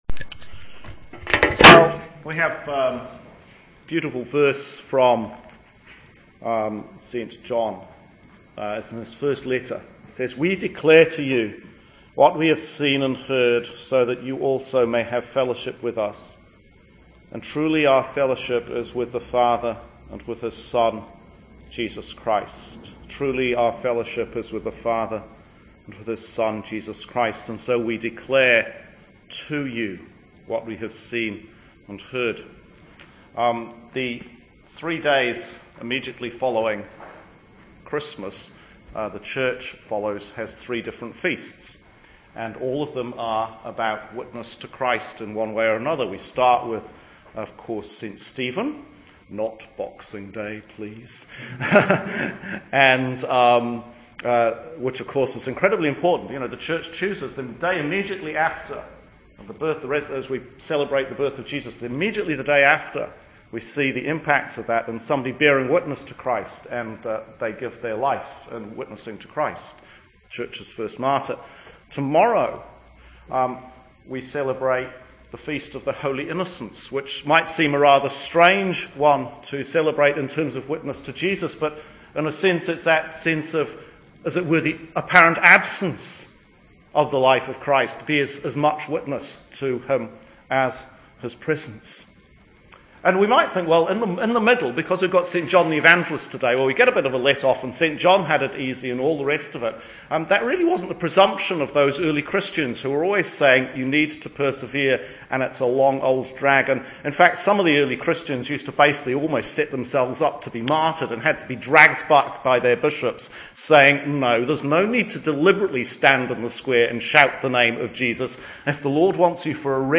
Sermon for the feast of St John the Evangelist 2015